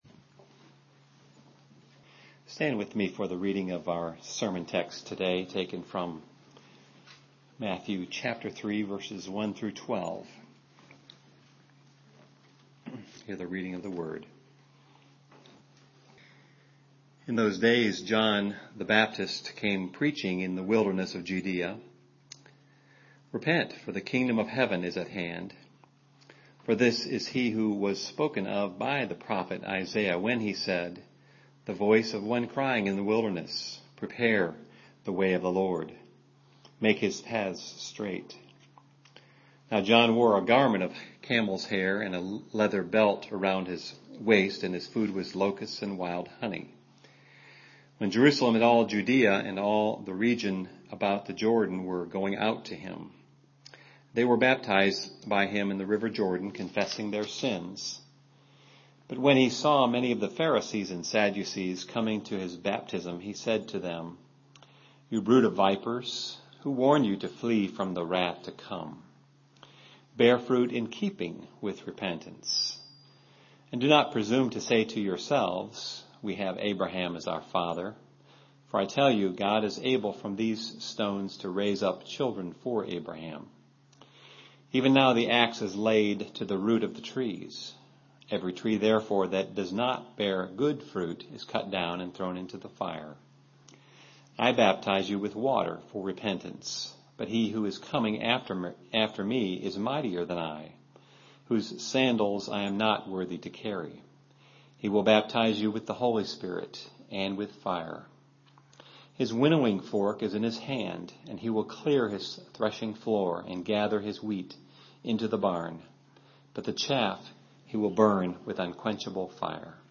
Categories: 2013, Advent, Matthew, Sermons, The Life and Ministry of Jesus